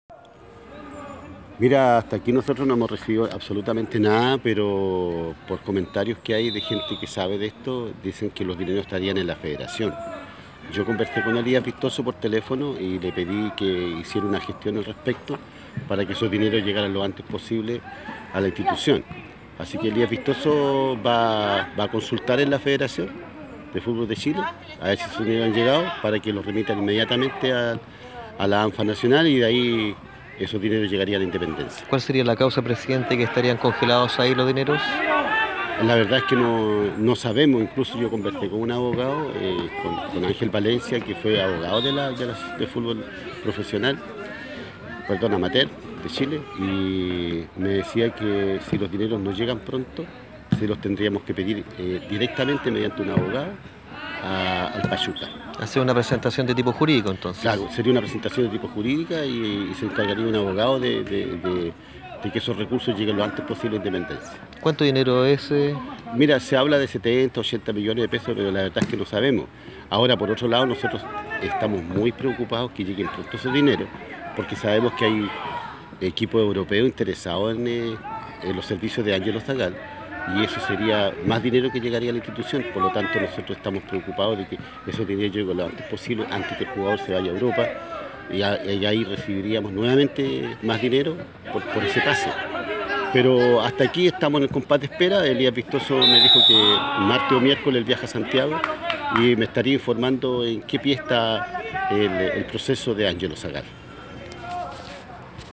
Declaraciones gentileza Gigante Deportivo